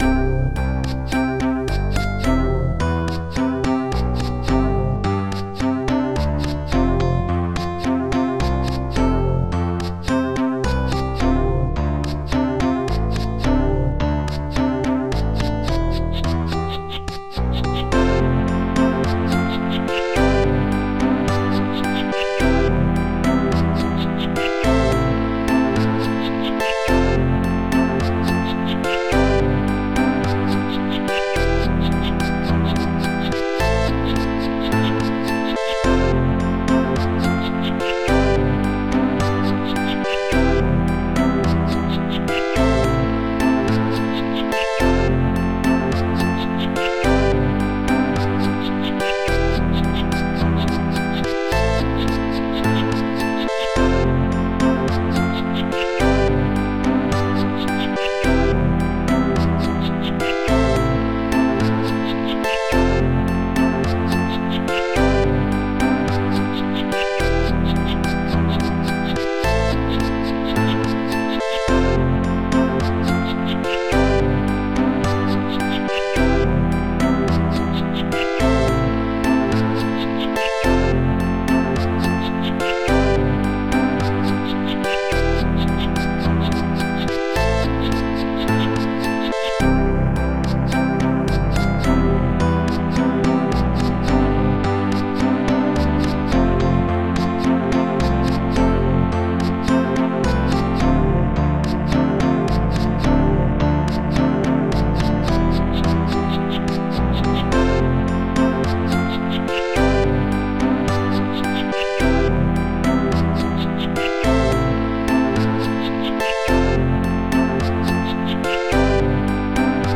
acou-guitar 3
bass
bass.synthit
rave_wave